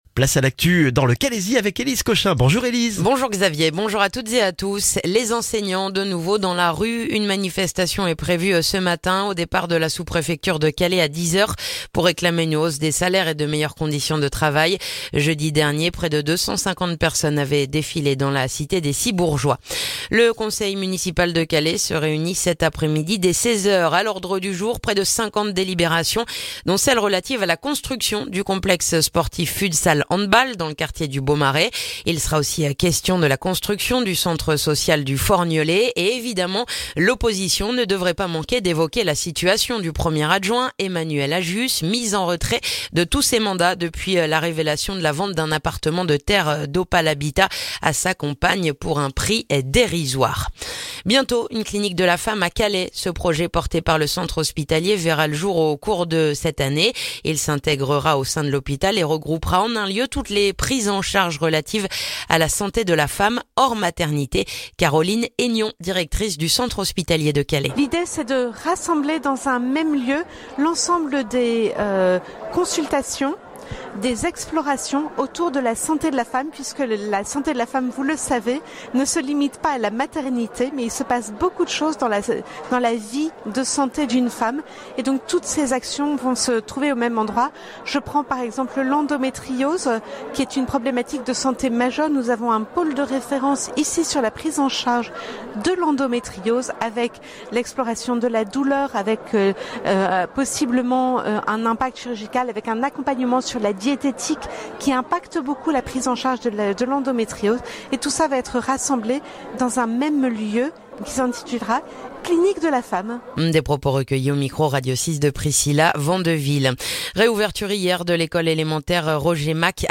Le journal du mardi 6 février dans le calaisis